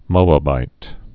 (mōə-bīt)